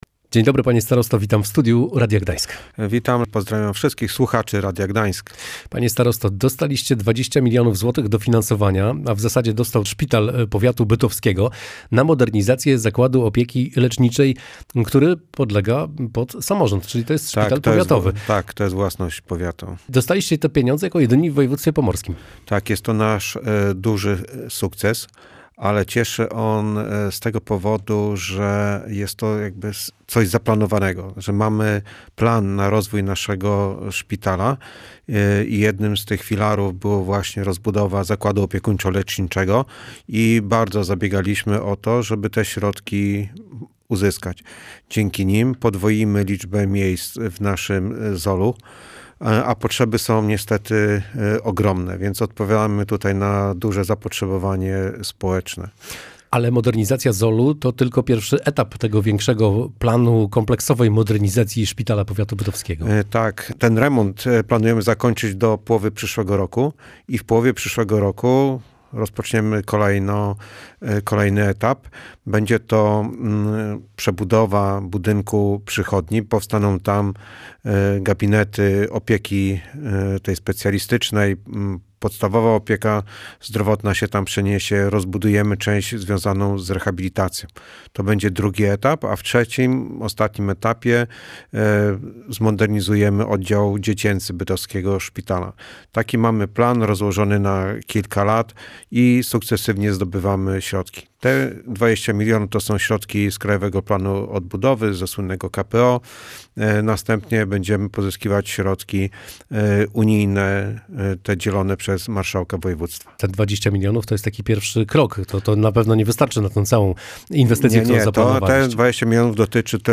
Powiat bytowski kupi 17 hybrydowych autobusów. Pięć z nich już wozi pasażerów, a pozostałe będą przekazywane sukcesywnie, ostatnie w połowie przyszłego roku – mówił na antenie Radia Gdańsk Leszek Waszkiewicz, starosta bytowski.